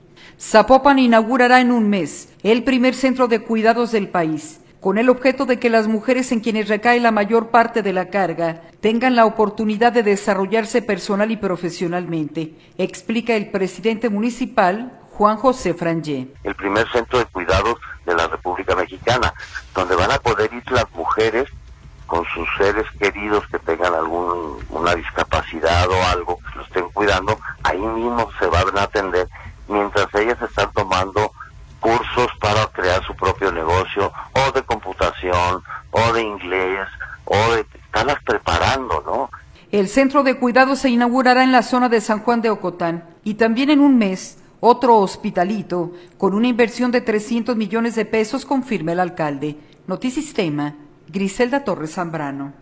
Zapopan inaugurará en un mes el primer centro de cuidados del país, con el objeto de que las mujeres en quienes recae la mayor parte de la carga, tengan la oportunidad de desarrollarse personal y profesionalmente, explica el presidente municipal, Juan José Frangié.